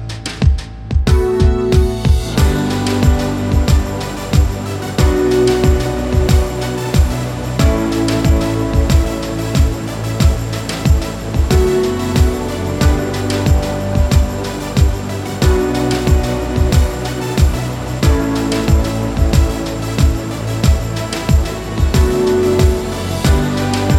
no Backing Vocals R'n'B / Hip Hop 3:43 Buy £1.50